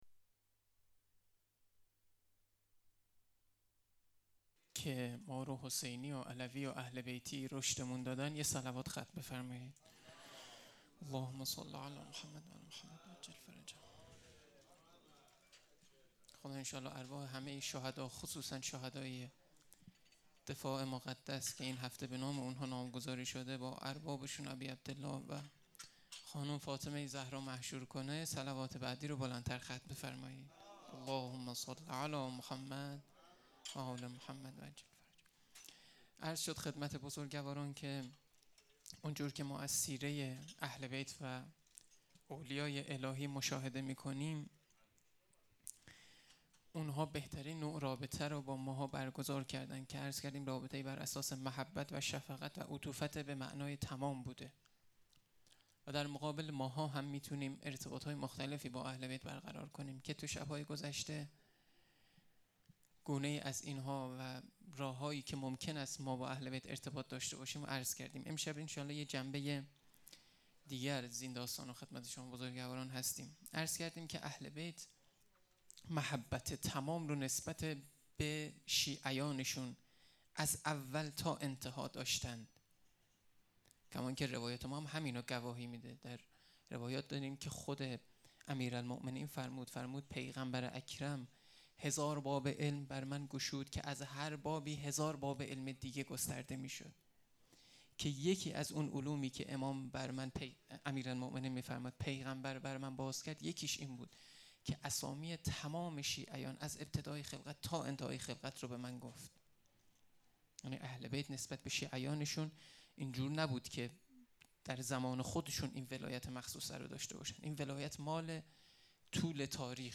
هیئت امیرالمؤمنین لهرانی های طالقان